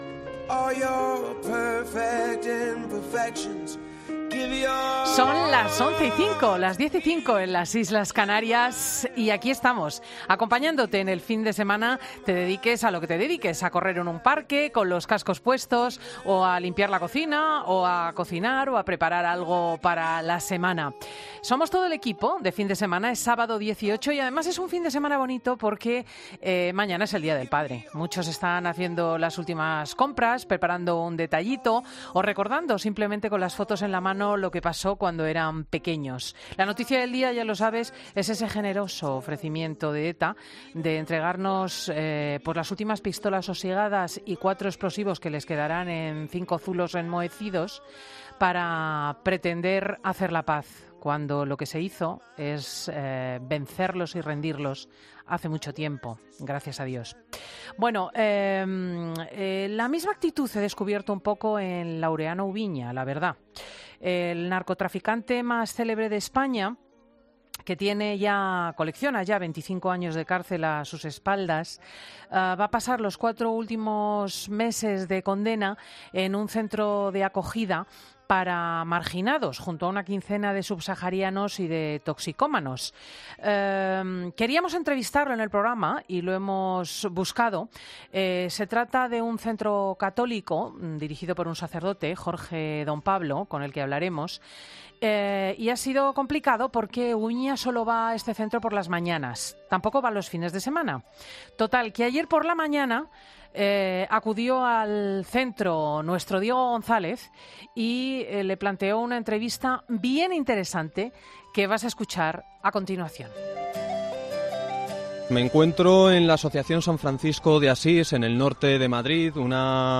Fin de semana entrevista a Laureano Oubiña